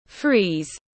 Freeze /friːz/